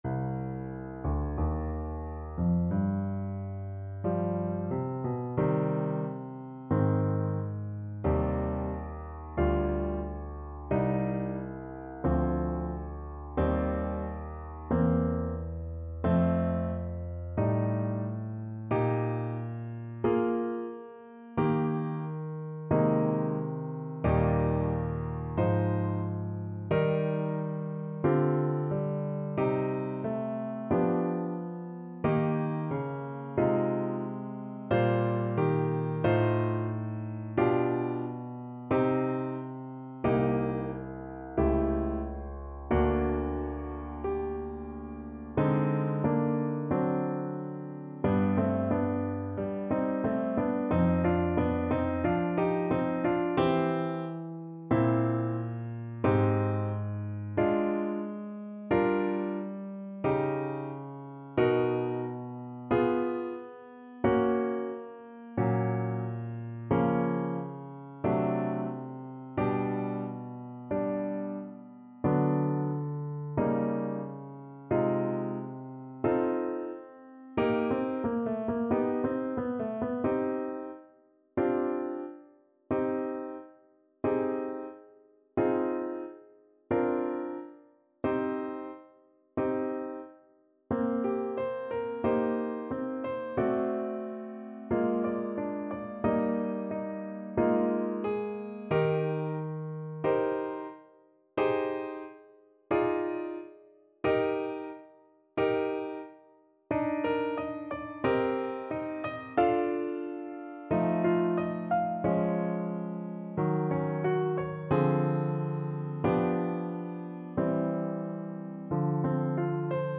4/4 (View more 4/4 Music)
Adagio, molto tranquillo (=60) =45
C minor (Sounding Pitch) D minor (Trumpet in Bb) (View more C minor Music for Trumpet )